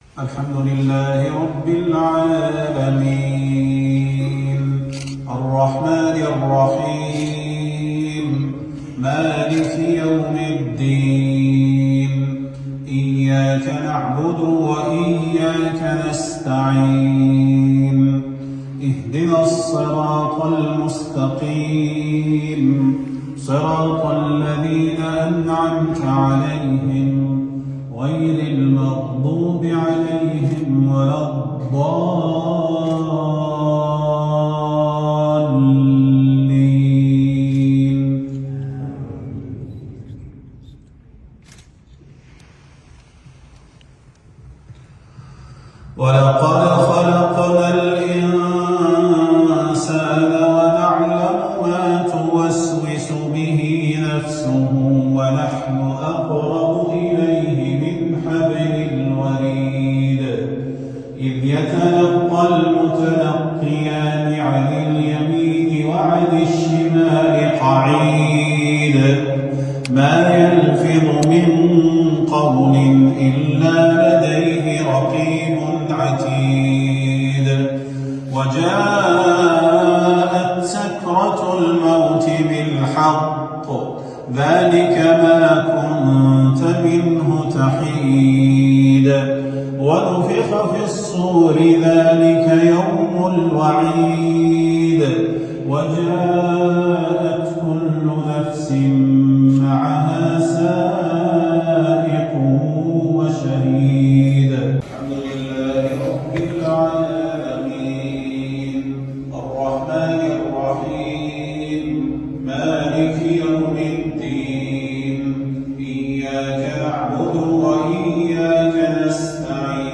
صلاة الجمعة للشيخ صلاح البدير 22 ربيع الآخر 1446هـ في دولة قيرغيزستان > زيارة الشيخ صلاح البدير لـ دولة قيرغيزستان > تلاوات و جهود الشيخ صلاح البدير > المزيد - تلاوات الحرمين